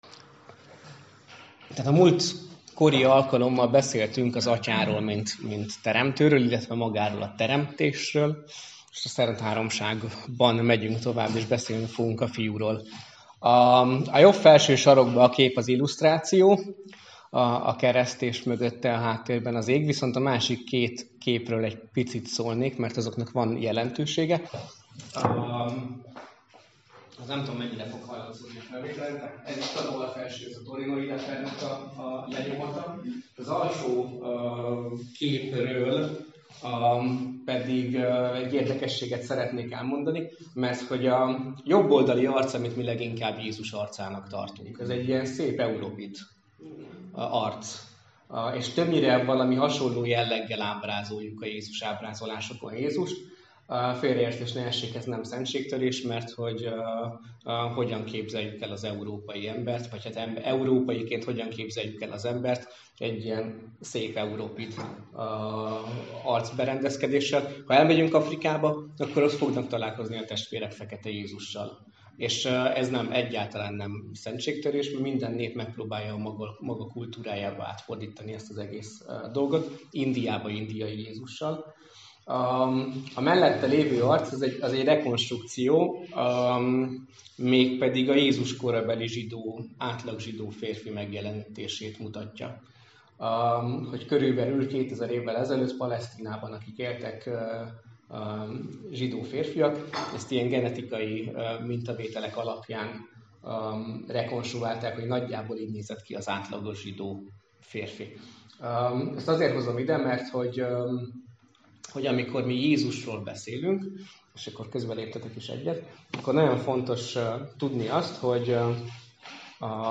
Mi az a Krisztológia? - Felnőtt hittan Mosonmagyaróváron.